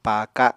[pjajo paakaɁ] n. rectangular wooden plate